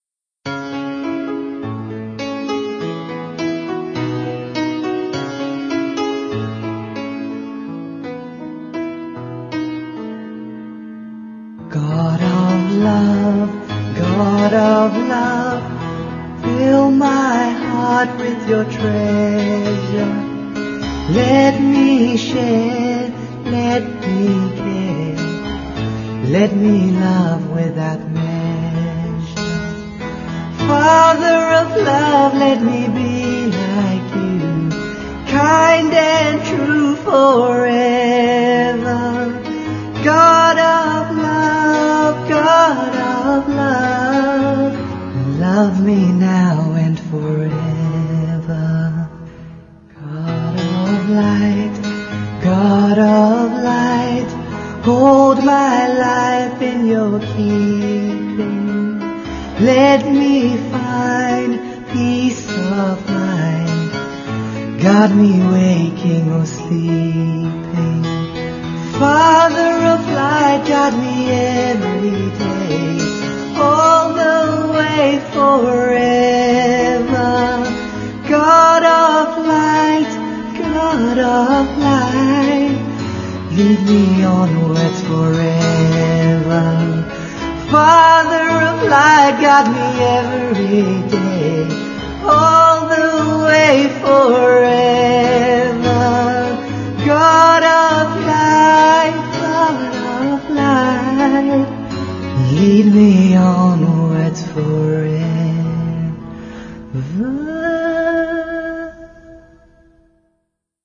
1. Devotional Songs
8 Beat / Keherwa / Adi
Medium Slow